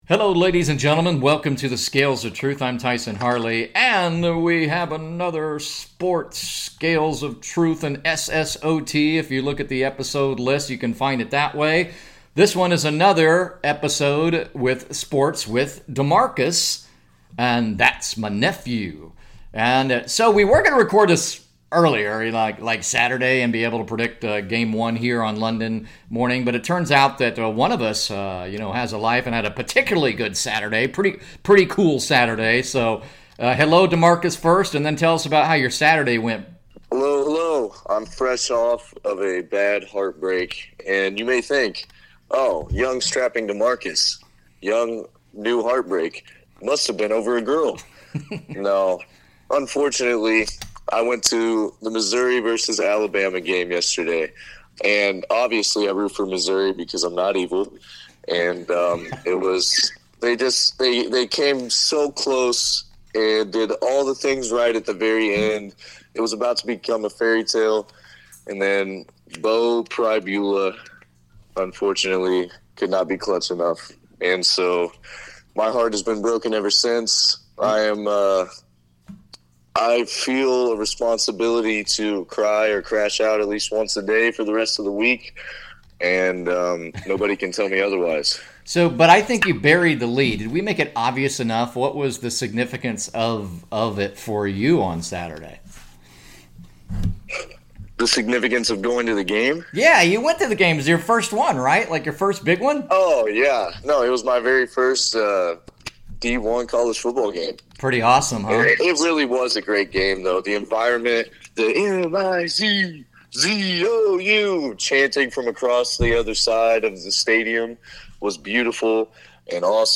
NOTE: Please be gracious regarding audio quality.